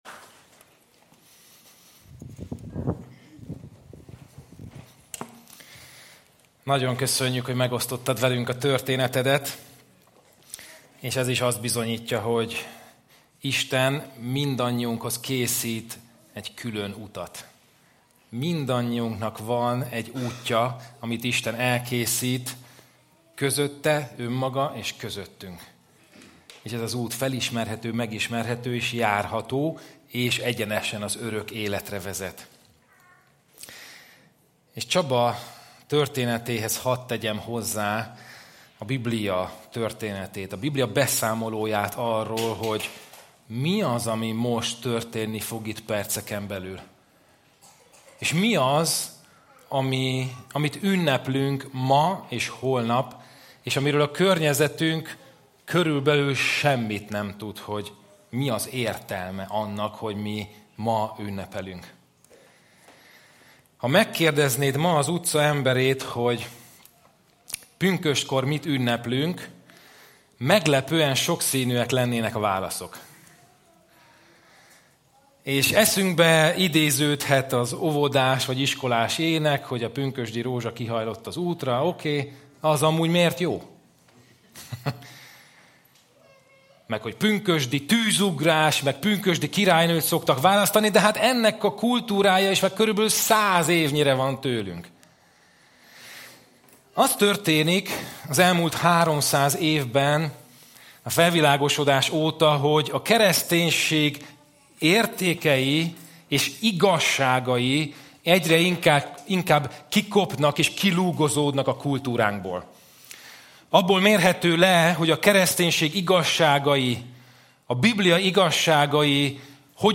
 Kategória: Igehirdetés  Sorozat: Pünkösd 2024  Like  Tweet  +1  Pin it